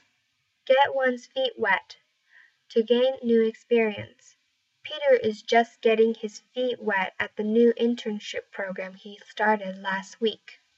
英語ネイティブによる発音は下記のリンクをクリックしてください。